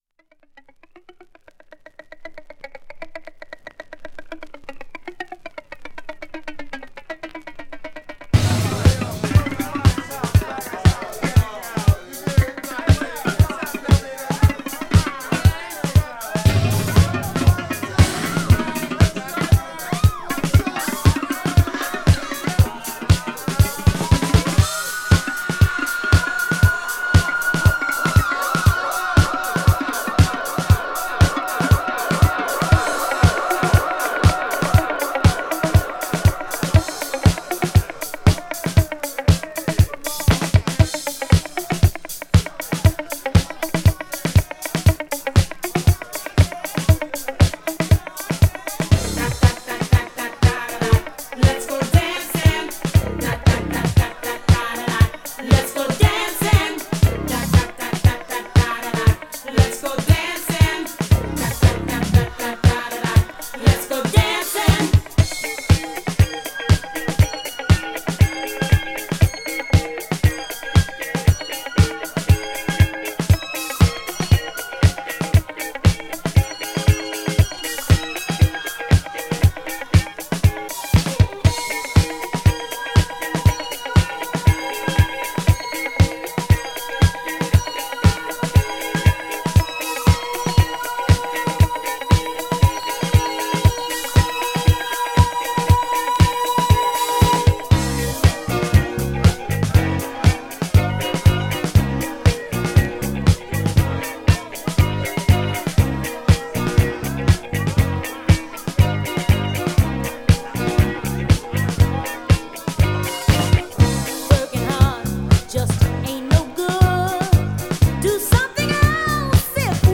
DANCE
チリチリ個所あります。